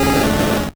Cri de Reptincel dans Pokémon Or et Argent.